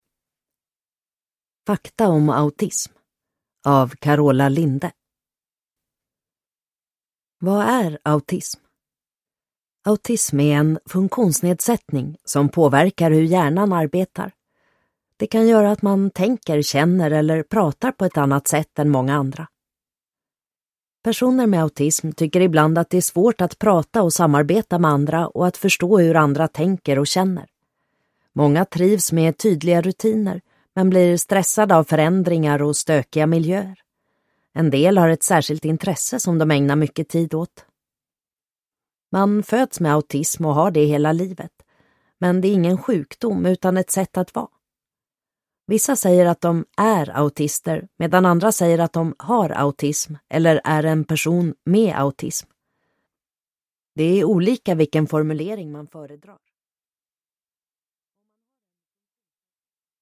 Fakta om autism – Ljudbok